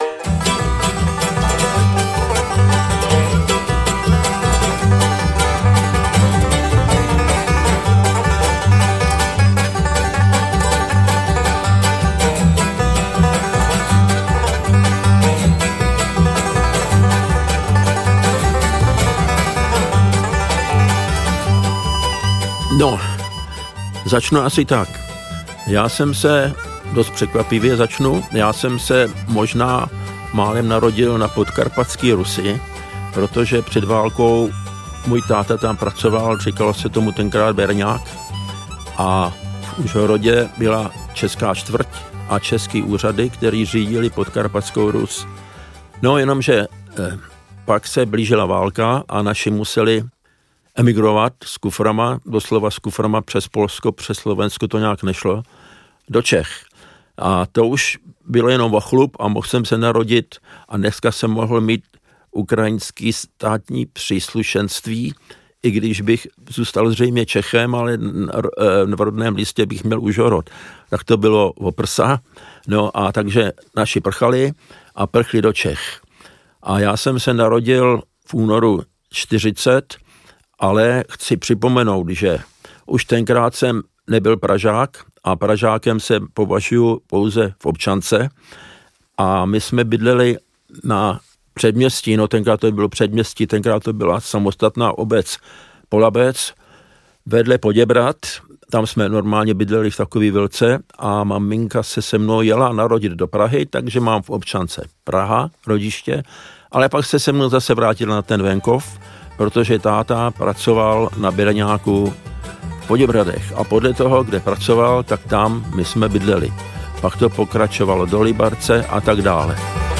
Interpret:  Marko Čermák
Zvukové vzpomínky legendárního výtvarníka a muzikanta Marko Čermáka Audiokniha pojednává o dlouhé životní cestě kreslíře a ilustrátora Rychlých šípů, který je světově uznávaným hráčem na pětistrunné banjo a byl dlouholetým členem legendární skupiny Greenhorns.